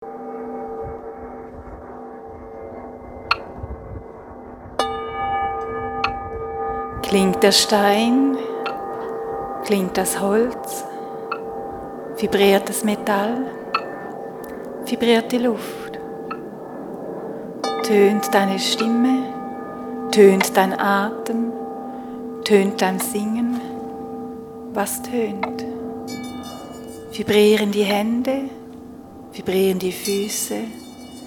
Tauche ein in die faszinierende Welt der Klangskulpturen.
Entdecke hörend das Klangspiel verschiedener Materialien.